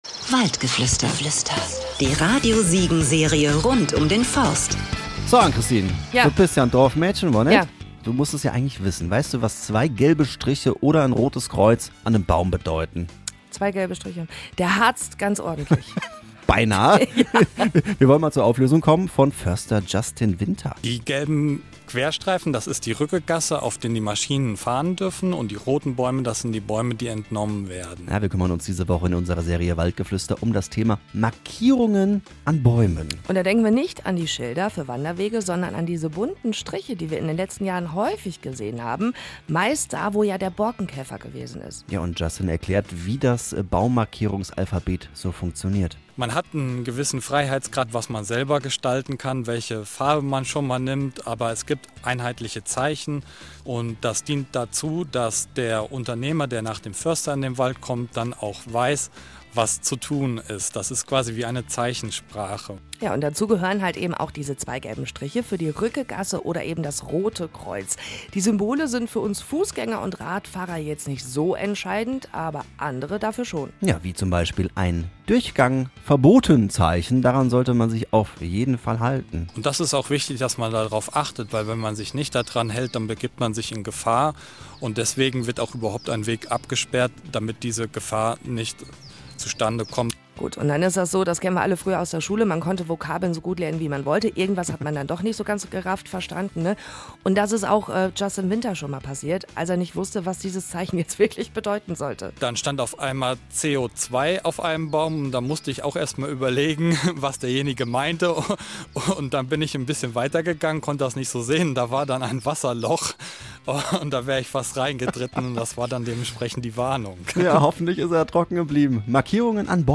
auf einen Spaziergang durch den Wald